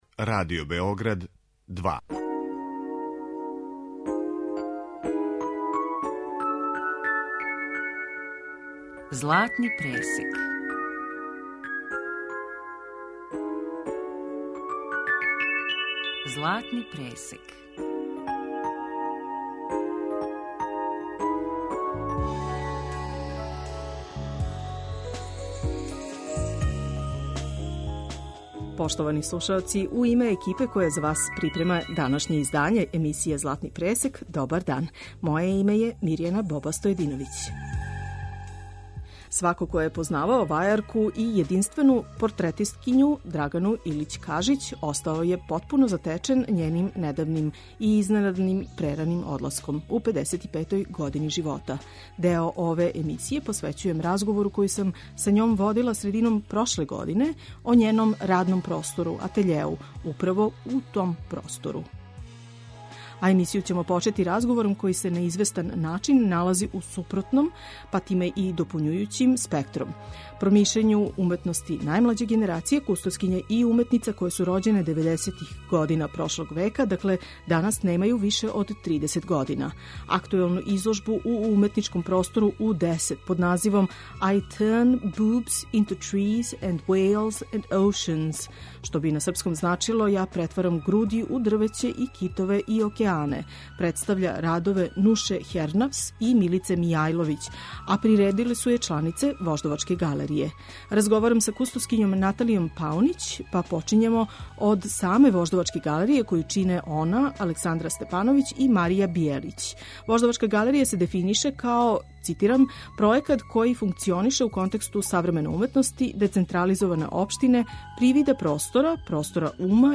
Имаћете прилику да чујете разговор са њом из 2018. године, када је говорила о свом скулпторском атељеу управо из њега самог, као и исечке разговора о њеним најскоријим изложбама у галерији Рима на којима је представила портрете низа ликовних критичара.